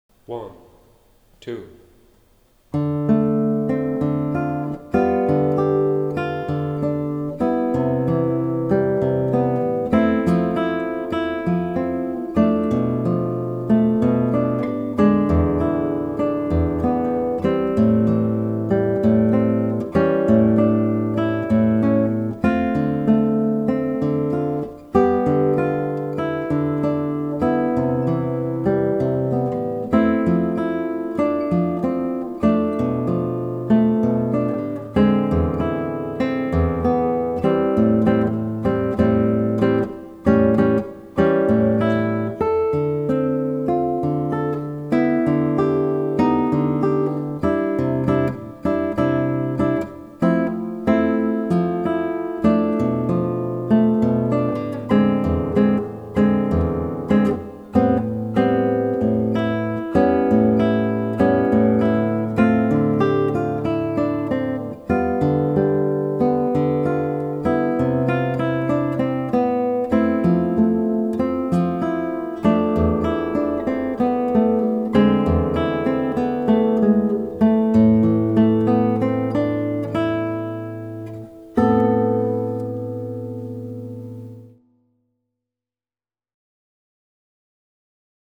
Solo-Version in verlangsamter Geschwindigkeit